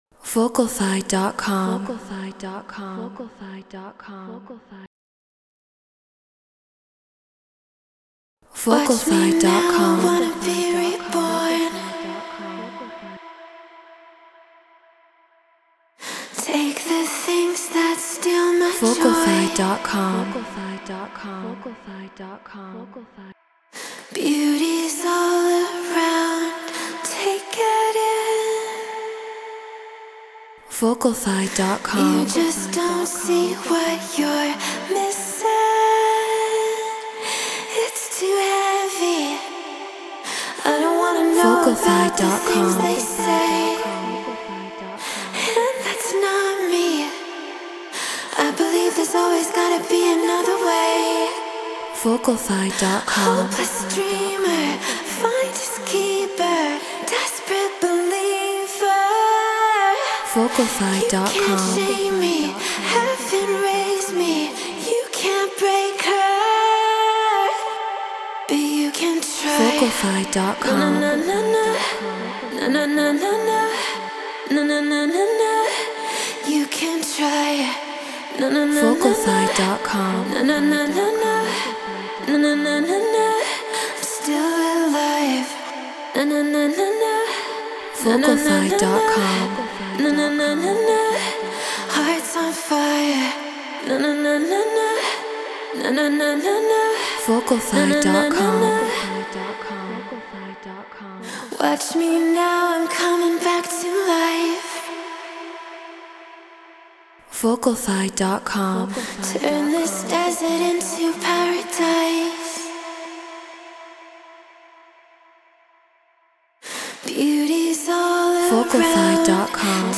House 124 BPM Dmin
Shure SM7B Apollo Twin X Logic Pro Treated Room